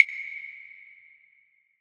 Destroy - SoulPerc.wav